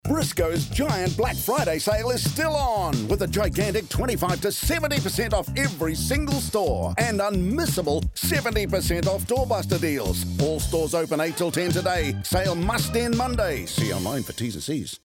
Here are some Black Friday audio ad samples that were heard across the world this year: